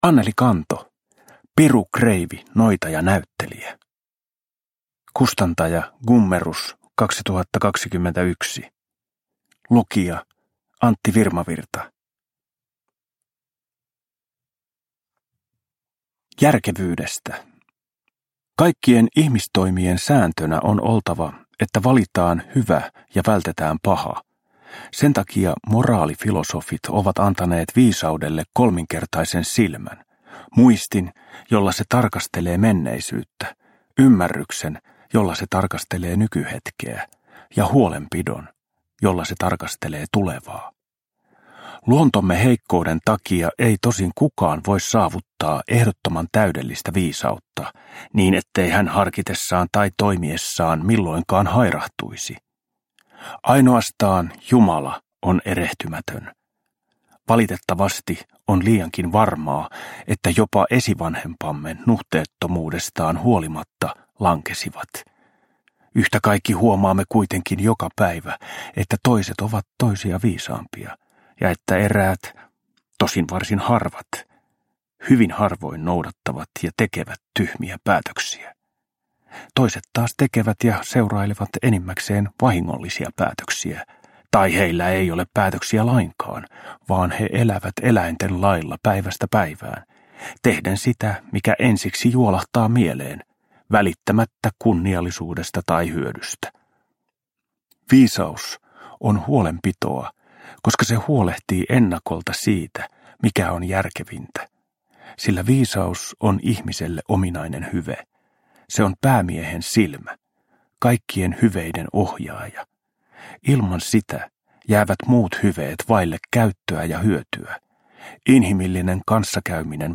Uppläsare: Antti Virmavirta